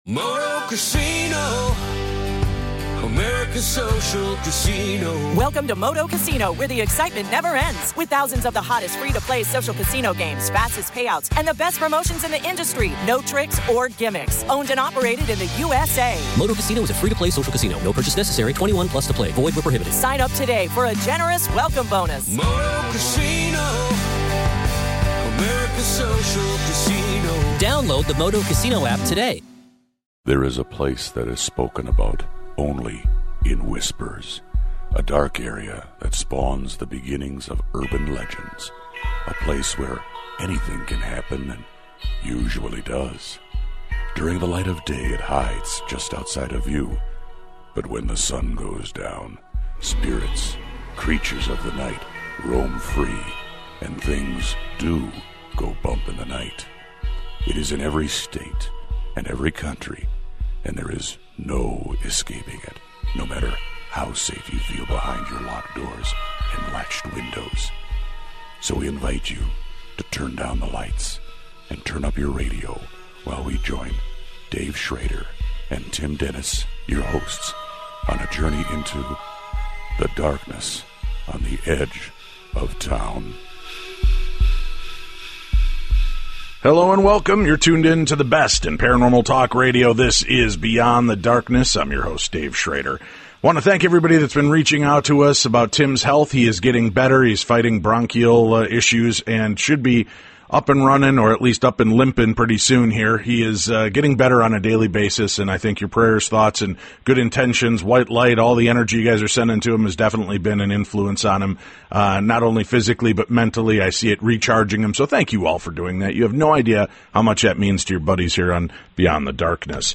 From high atop a car park in the faraway and hurricane riddled St. Thomas in the US Virgin Isles Fiona Horne chats...